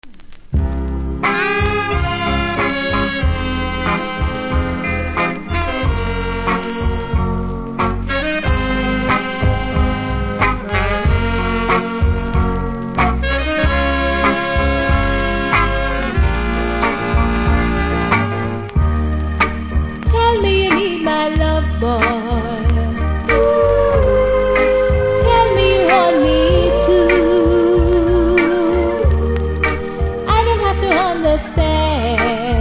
ラベルの見た目悪いですが音は良好なので試聴で確認下さい。